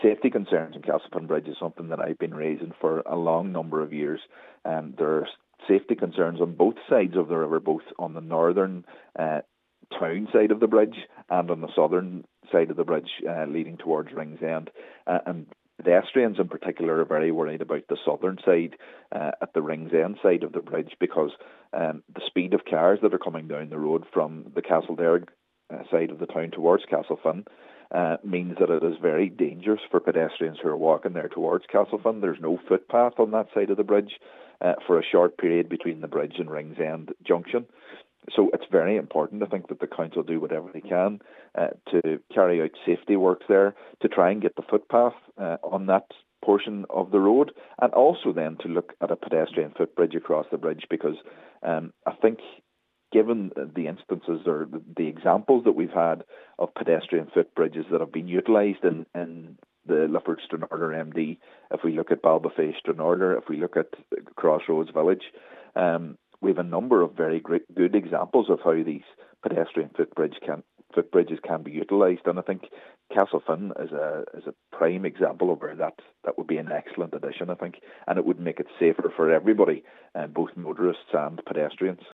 Councillor Gary Doherty says in its current form, the bridge is unsafe.